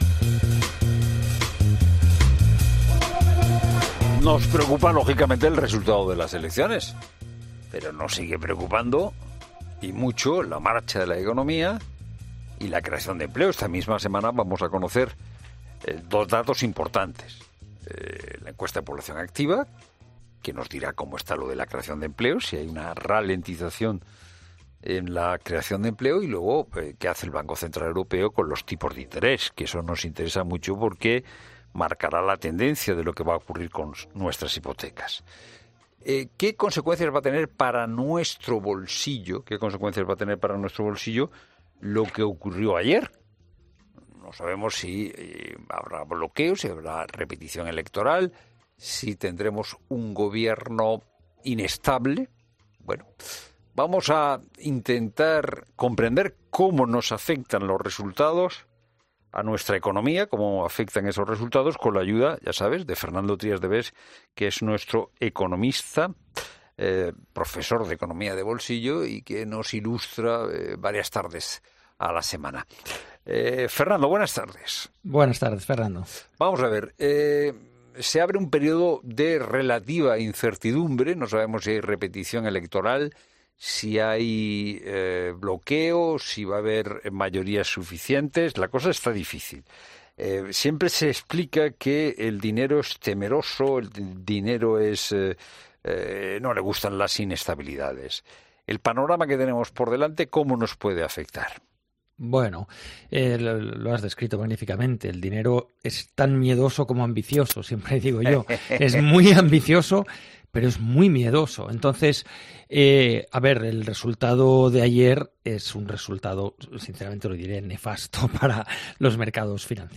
Junto a Fernando De Haro, el economista y escritor Fernando Trías de Bes trata de responder a todas ellas en 'La Tarde'.